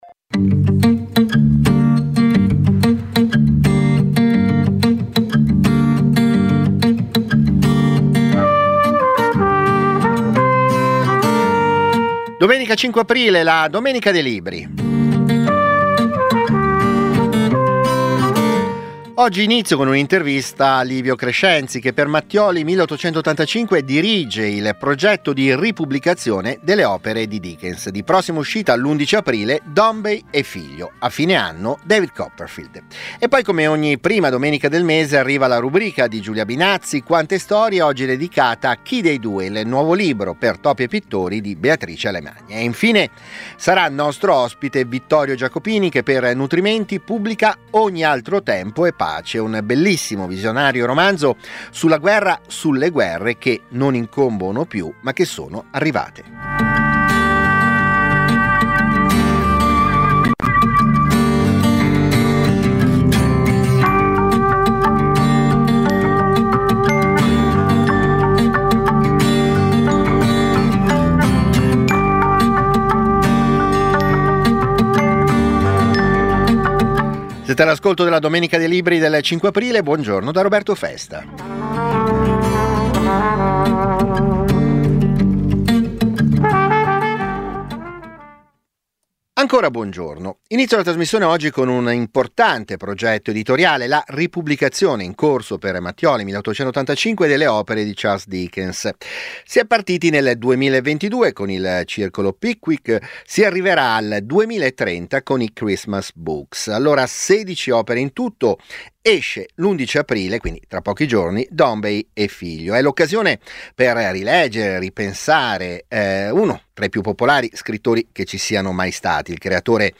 Ogni domenica, dalle 10.35 alle 11.30, interviste agli autori, approfondimenti, le novità del dibattito culturale, soprattutto la passione della lettura e delle idee.